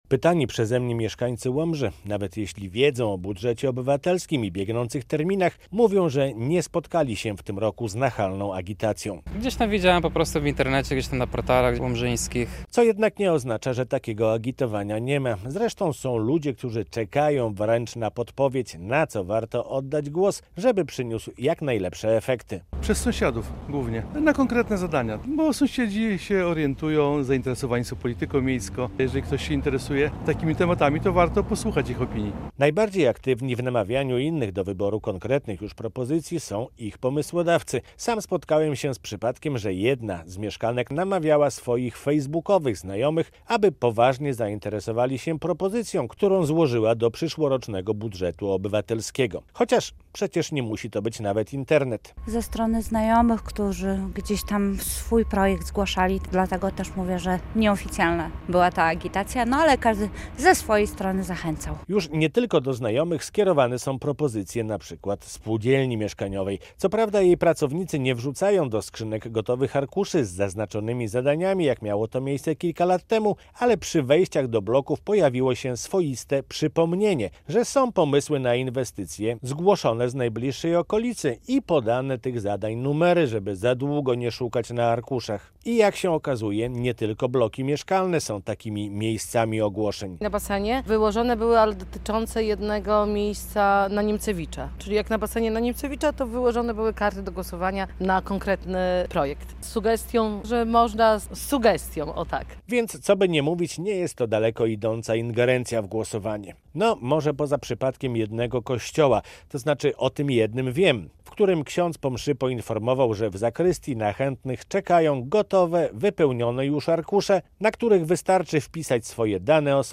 "Pomoc" w głosowaniu na Budżet Obywatelski w Łomży - felieton